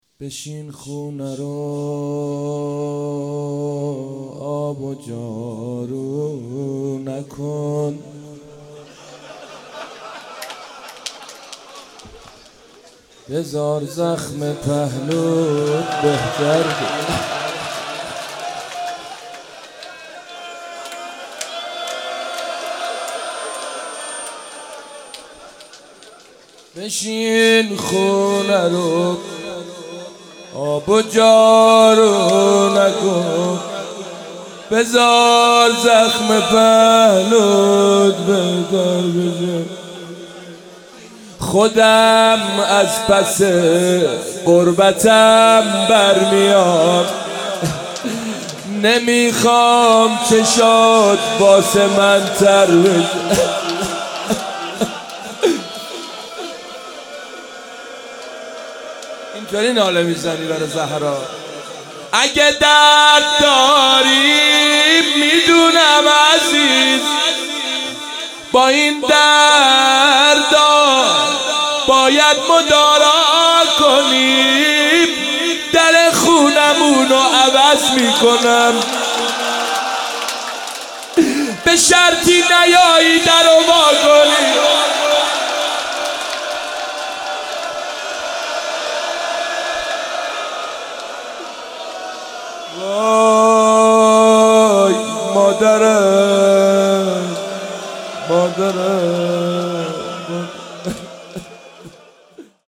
ایام فاطمیه 95 - 7 اسفند 95 - روضه - بشین خونه رو آب و جارو